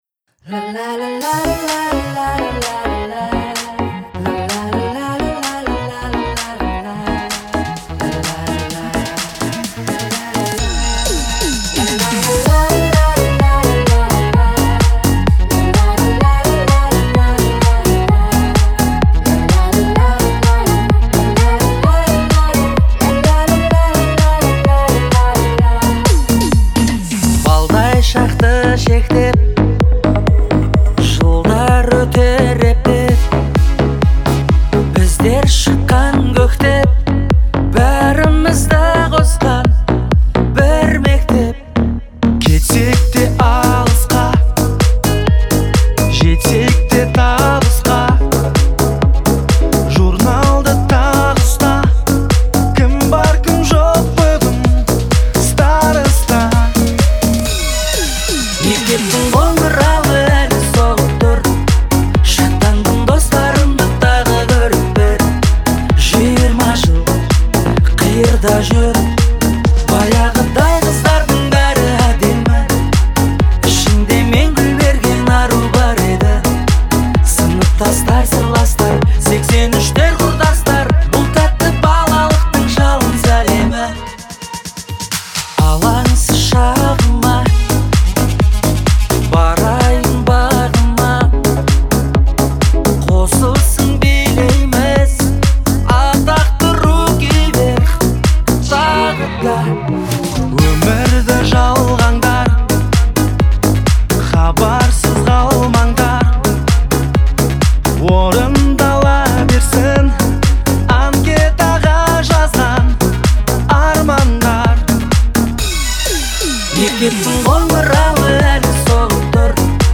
это динамичная композиция в жанре хип-хоп